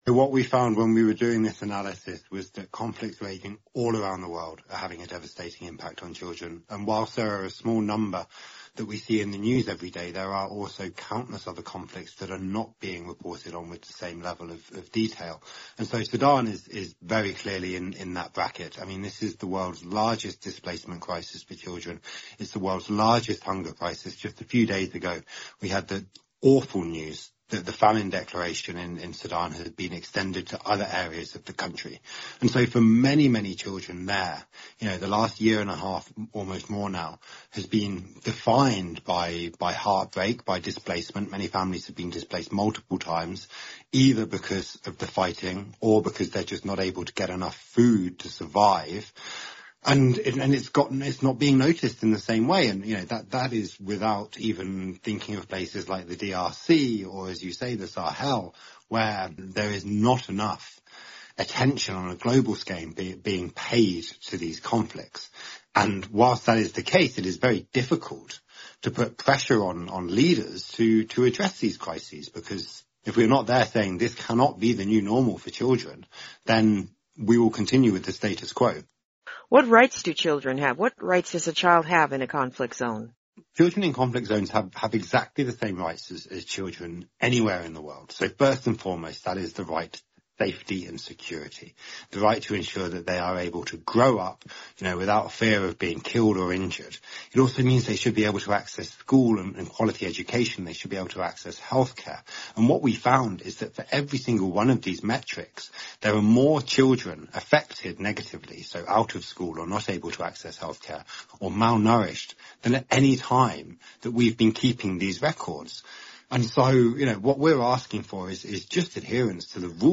Daybreak Africa is a 30-minute breakfast show looks at the latest developments on the continent and provides in-depth interviews, and reports from VOA correspondents.